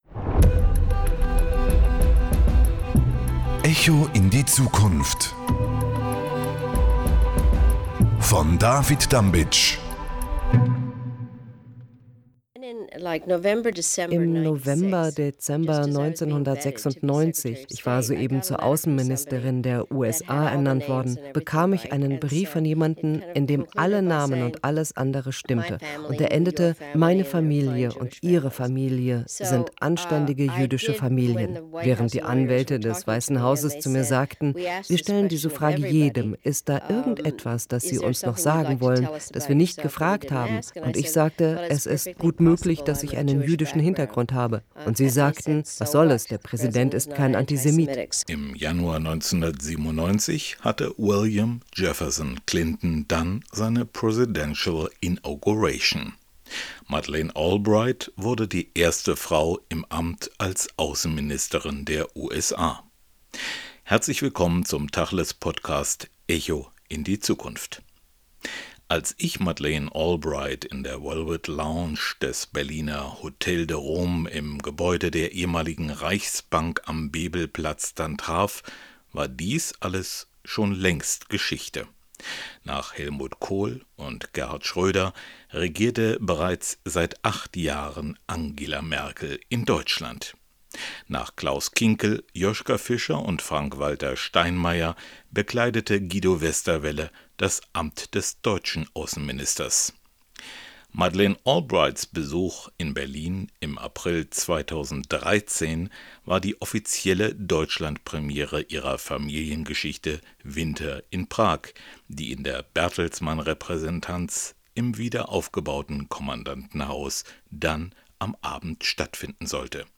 Madeleine Albright hat als Aussenministerin Humanität für die amerikanische Aussenpolitik neu formuliert. Der Podcast «Echo in die Zukunft» präsentiert das Gespräch über Wurzeln, Politik und Antisemitismus.